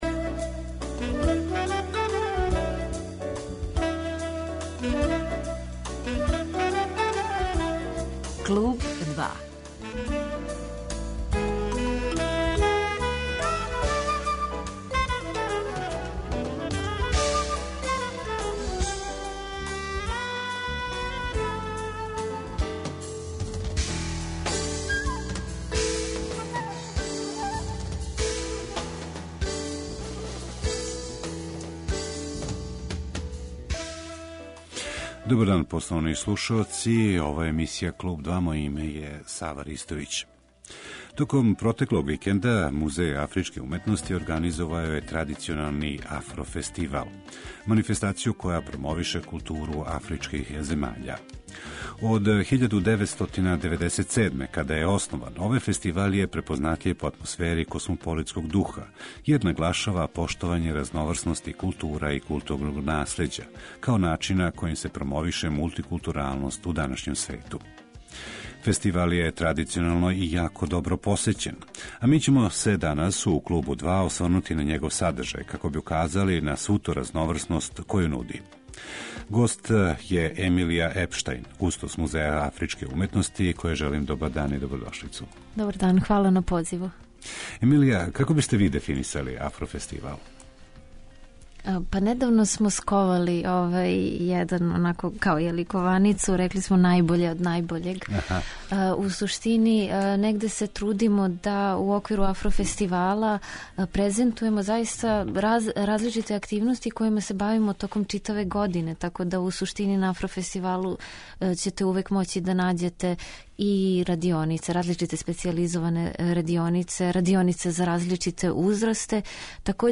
Гост емисије 'Клуб 2', у којој ће се говорити о Афро фестивалу